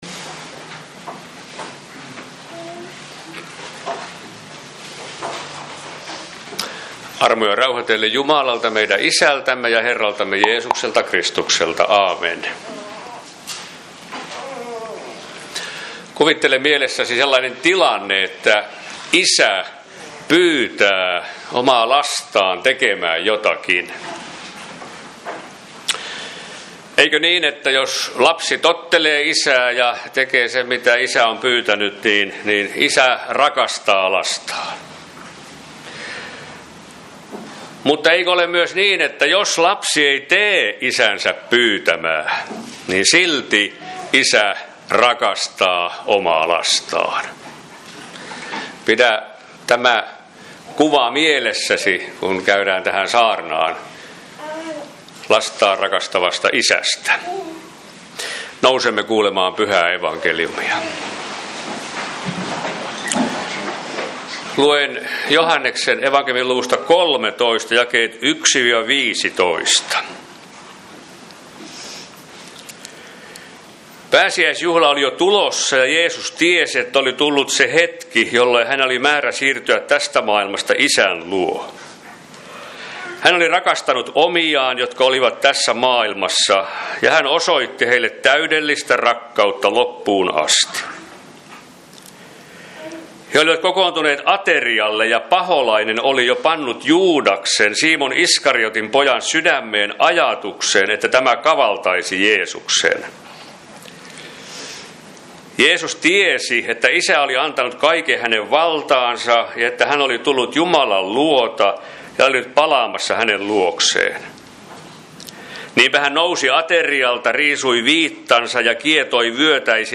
Kokoelmat: Seinäjoen Hyvän Paimenen kappelin saarnat